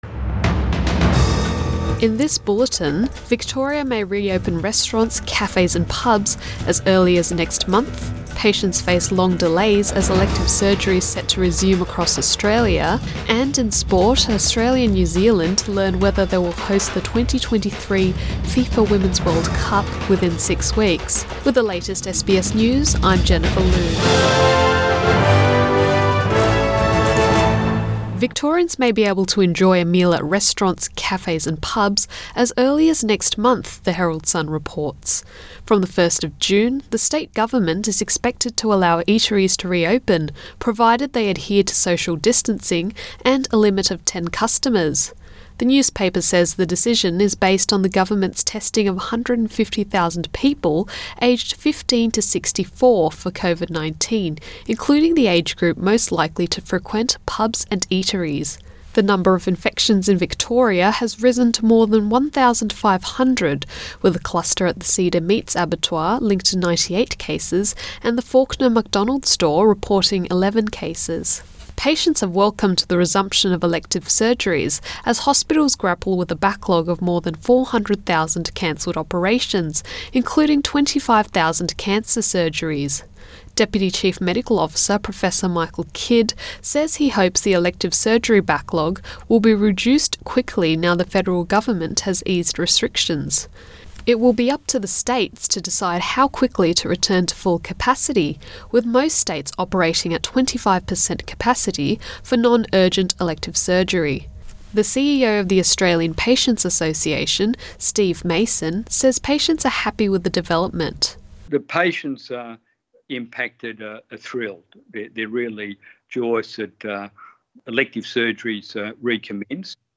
AM bulletin 17 May 2020